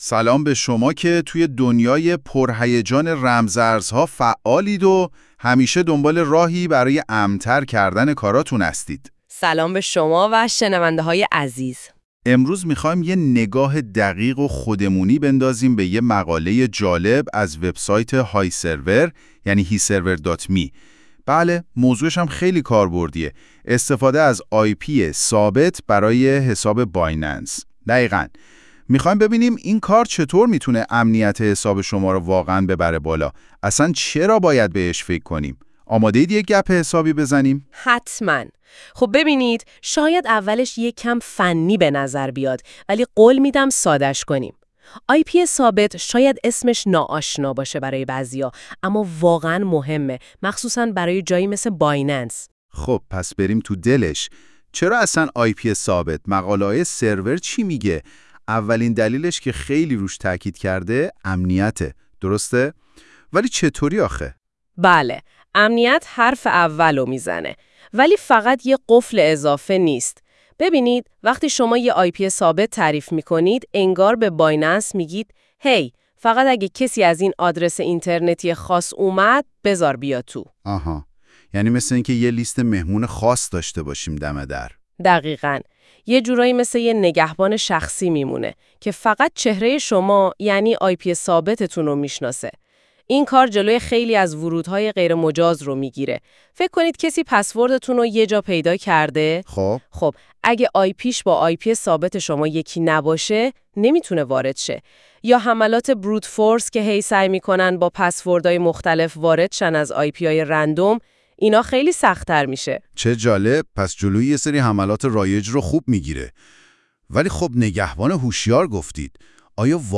خلاصه صوتی مقاله: